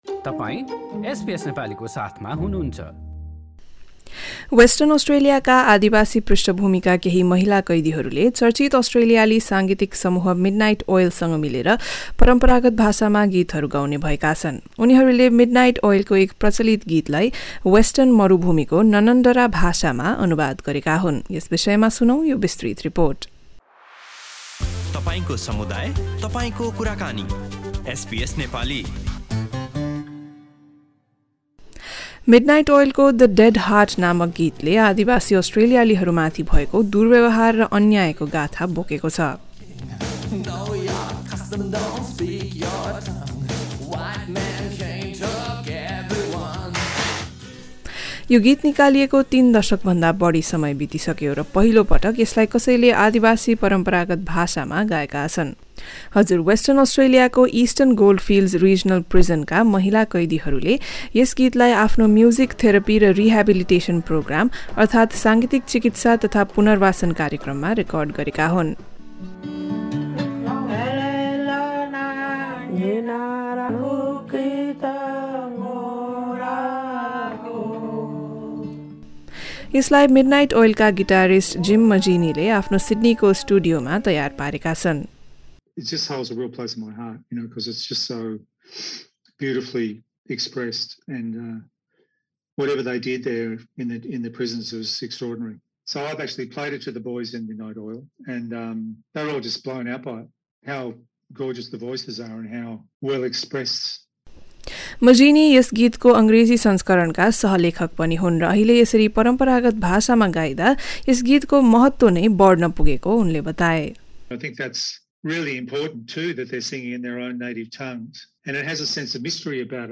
गीतसहितको पूरा रिपोर्ट यहाँ सुन्नुहोस्: द डेड हार्ट: आदिवासी अस्ट्रेलियालीहरू माथि भएको दुर्व्यवहार र अन्यायको गाथा हाम्रा थप अडियो प्रस्तुतिहरू पोडकास्टका रूपमा उपलब्ध छन्।